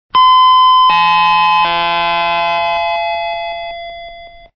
• Passenger door light emitting diode (LED) indicators with
electronic door chimes
00 C Set Doors Closing Chime.mp3